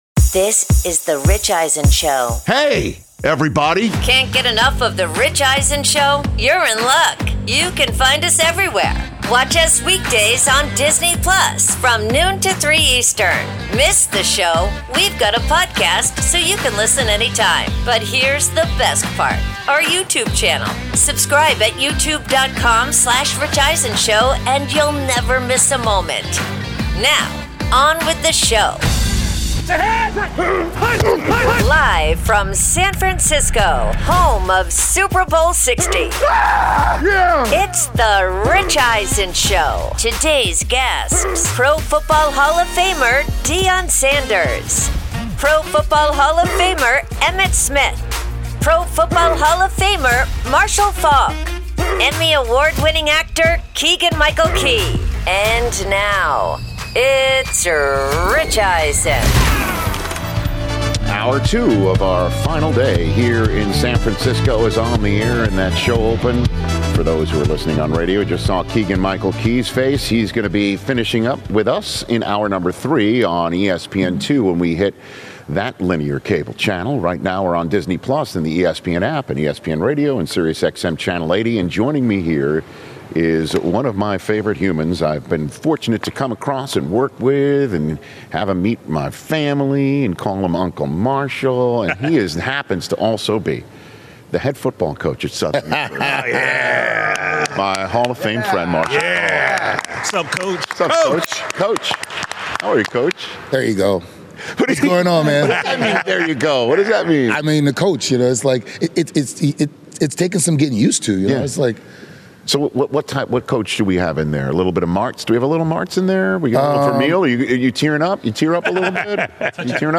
Hour 2: Live from Super Bowl LX with Marshall Faulk, Stafford Wins MVP Reaction Podcast with Rich Eisen
Live from Super Bowl LX in San Francisco